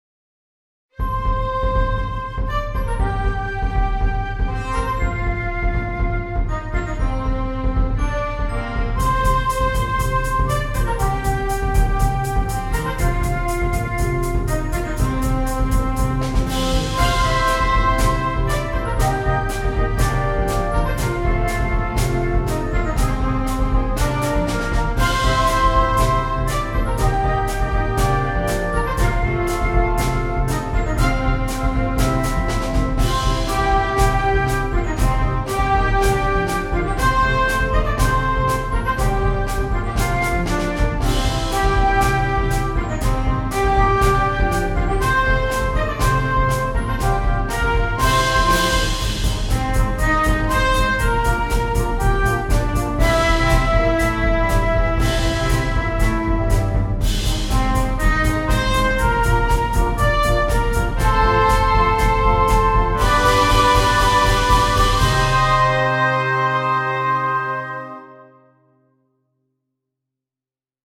Virtual recordings were made using NotePerfomer 3.